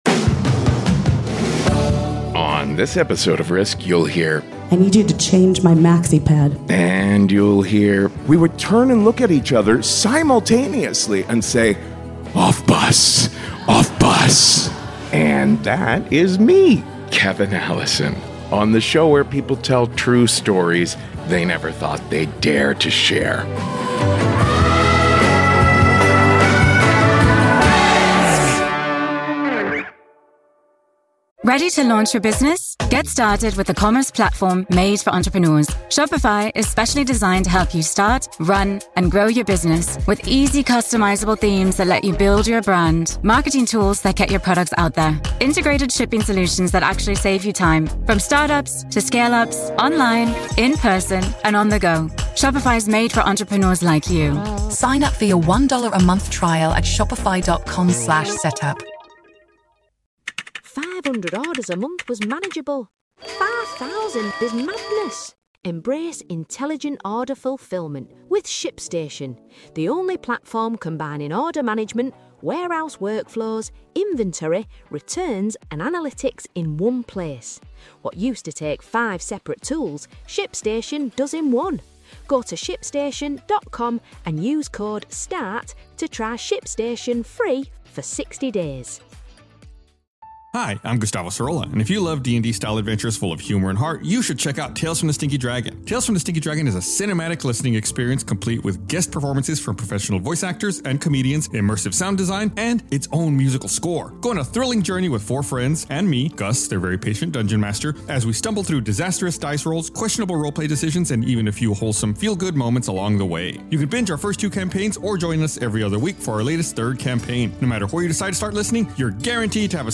Bad Medicine brings together three storytellers whose bodies, doctors, and circumstances all conspire against them in very different ways.